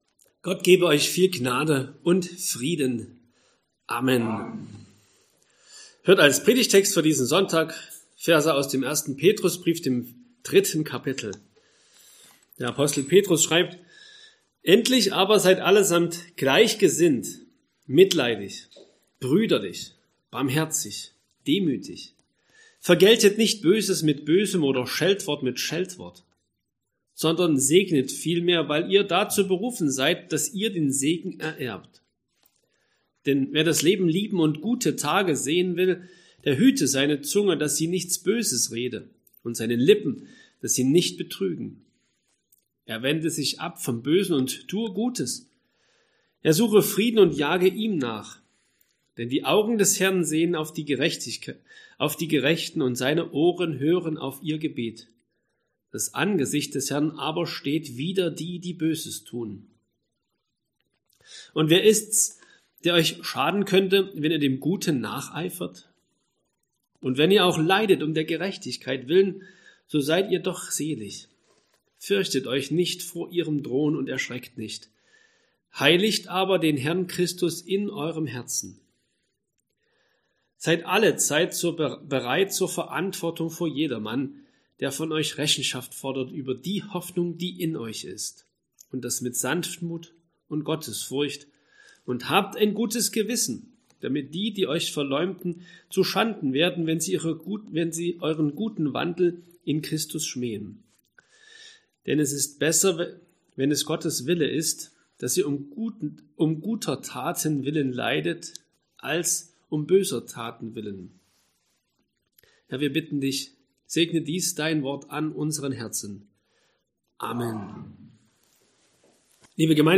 Sonntag nach Trinitatis Passage: 1. Petrus 3, 11-17 Verkündigungsart: Predigt « 3.